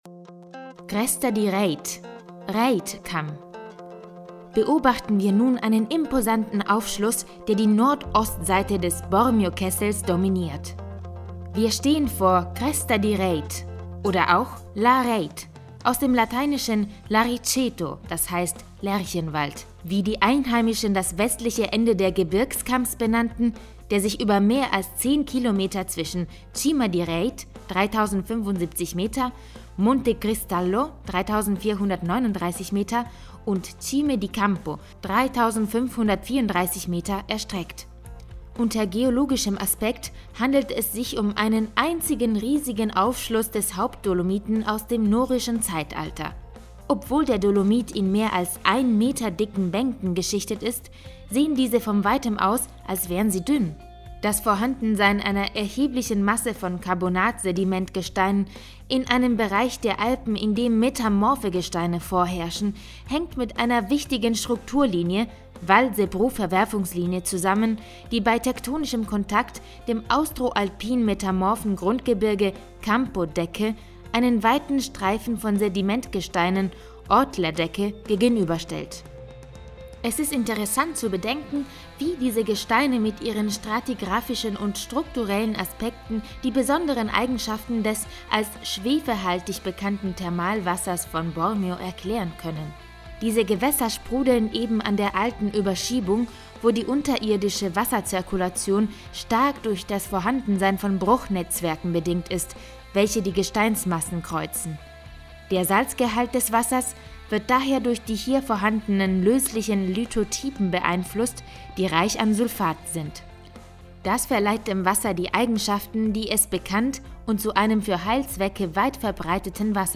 AUDIOGUIDE